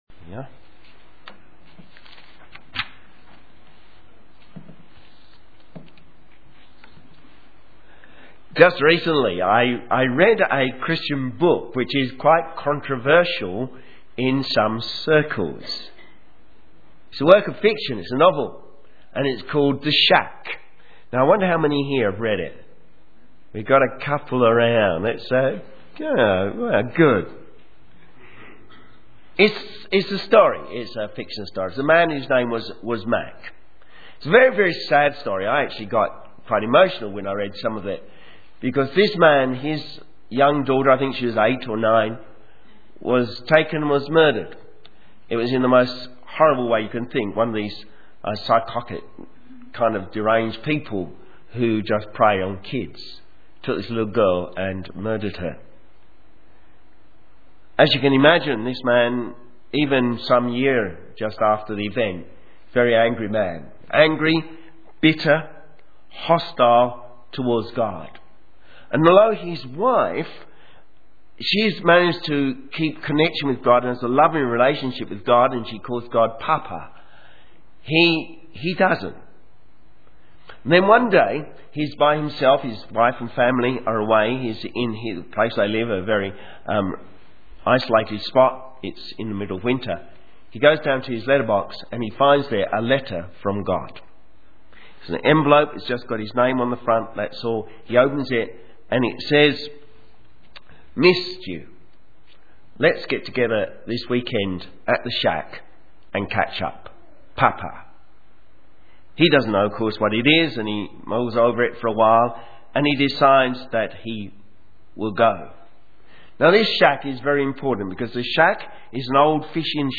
Sermon
Divine Mothering Luke 13:22-35 Synopsis A Mother's Day sermon that looks at the mothering characteristic displayed by God towards His people.